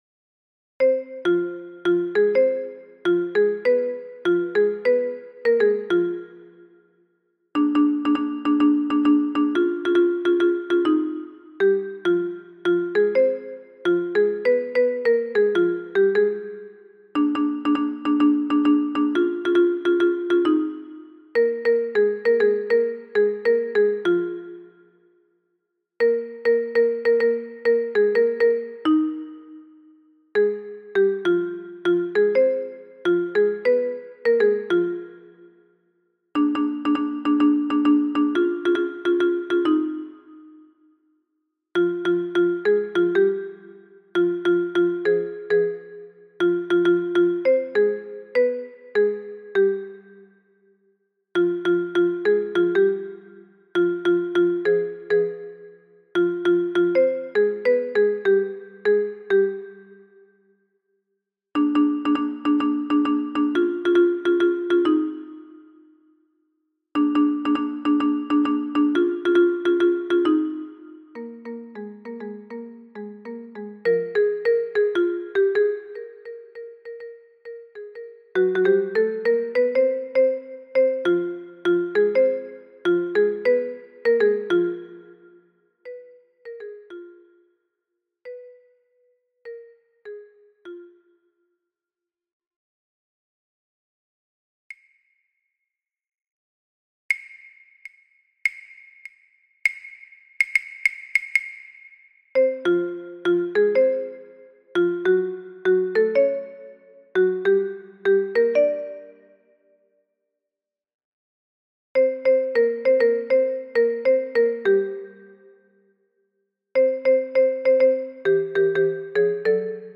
Alto / Basse et autres voix en arrière-plan